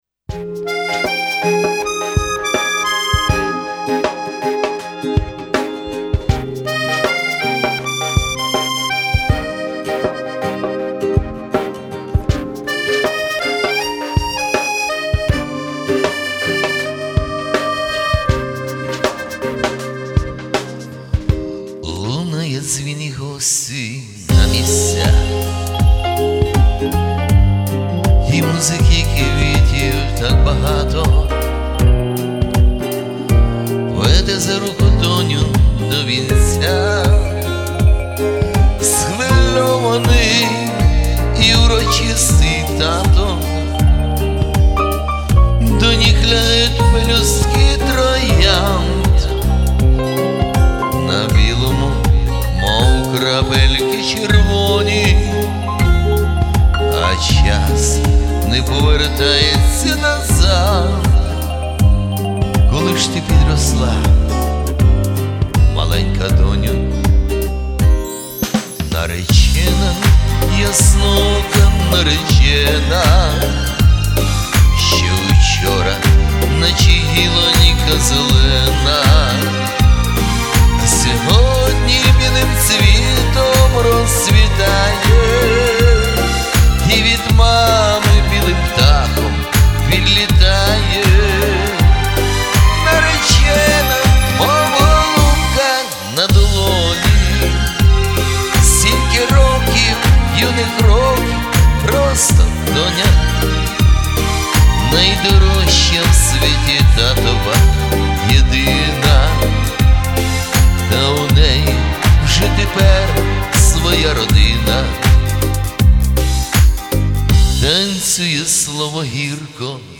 Soundcraft UI-12 Мікрофон Beyerdynamic Tg v35s